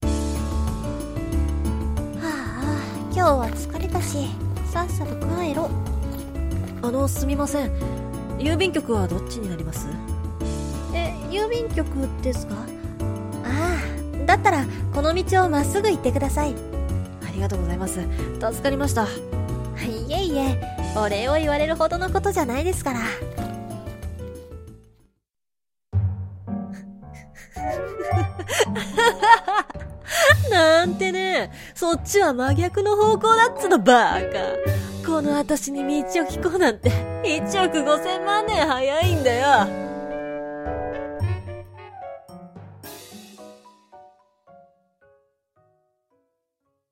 二重人格少女と道を聞く少年。